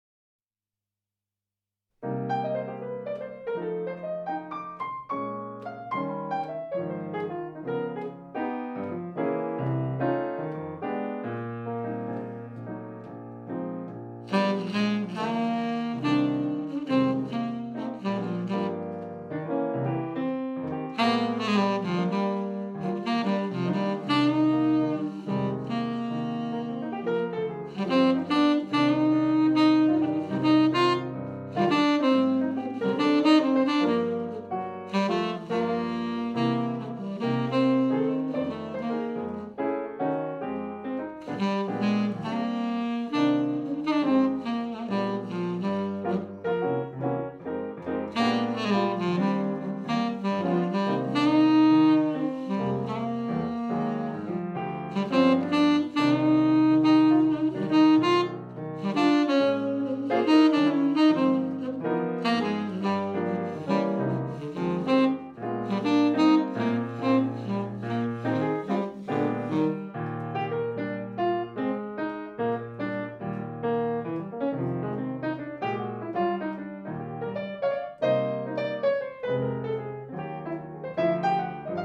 Natural Sound Recording
爵士音樂/發燒天碟